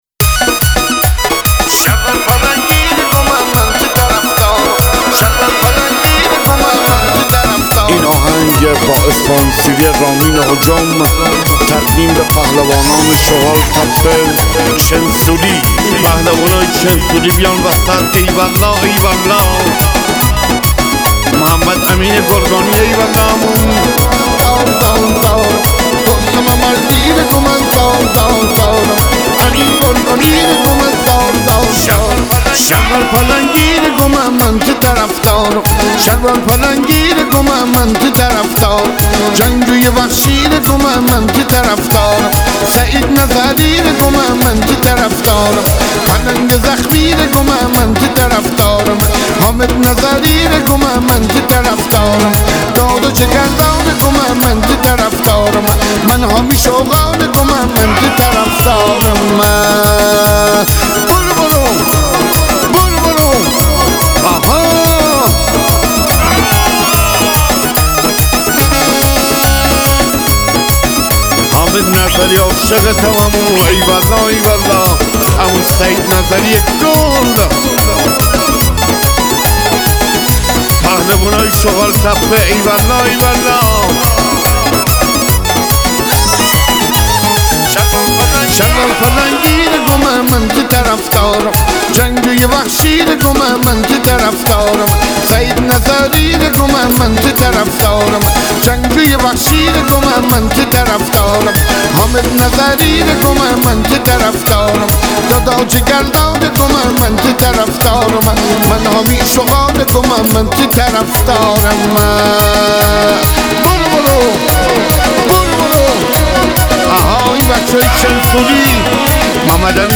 ۱۰ اهنگ برتر مازندرانی لاتی شاد به صورت گلچین شده